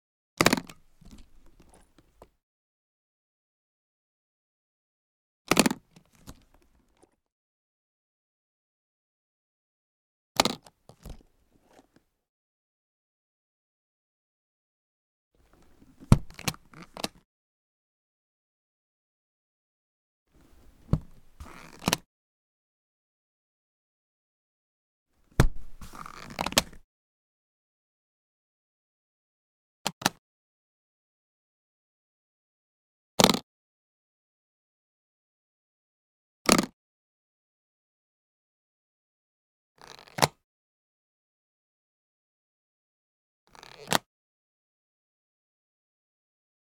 household
Briefcase Hard Cover Leather Unlatch and Open